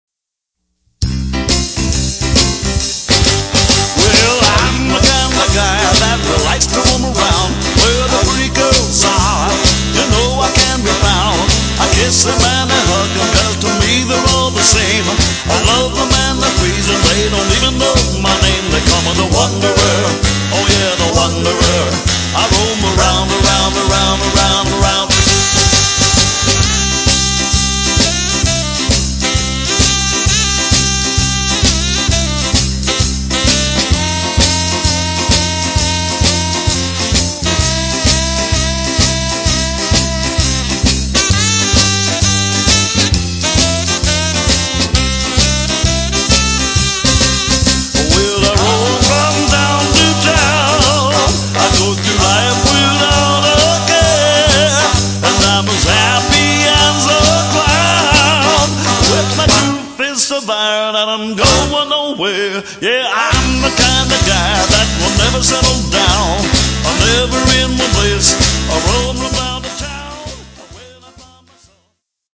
Oldies / Rock